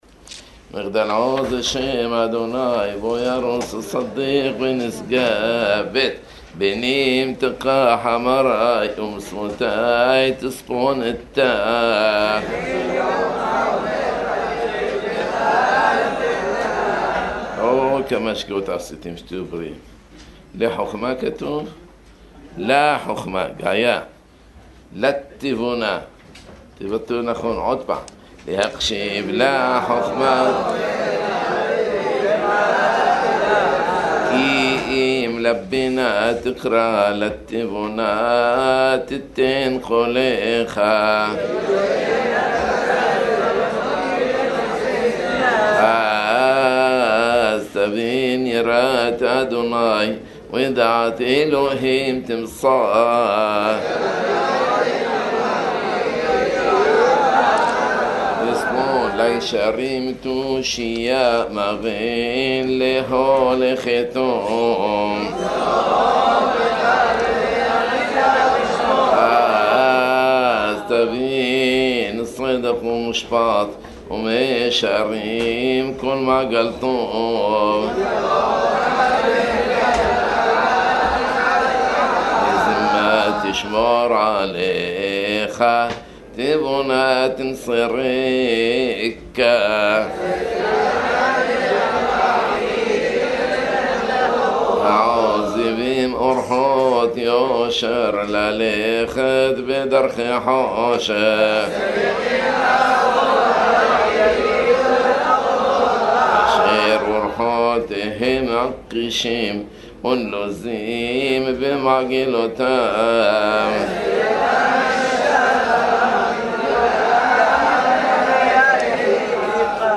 קריאת ספר משלי עם הבחורים ופירוש הפסוקים בטוב טעם ודעת בפירושים מיוחדים ויפים מאוד